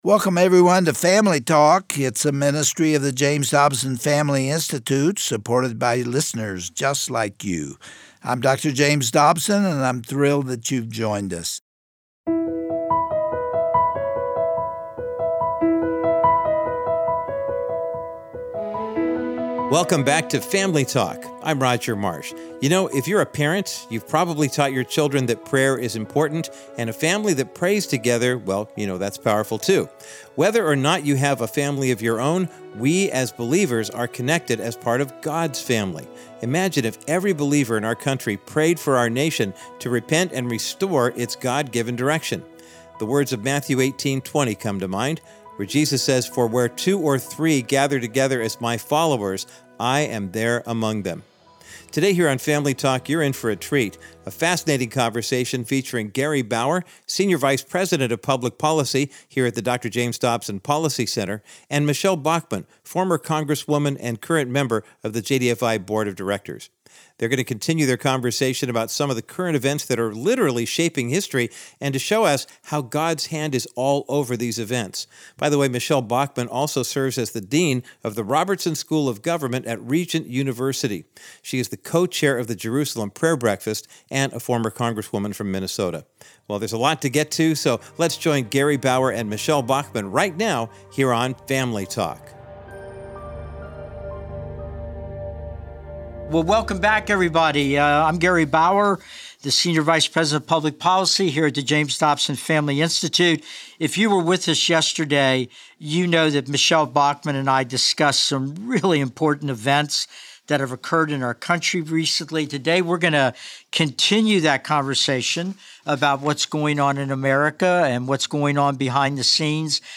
Today on Family Talk, Gary Bauer concludes his riveting discussion with the Hon. Michele Bachmann about the current state of our nation. They also discuss how the mighty hand of God has protected the United States of America since her birth.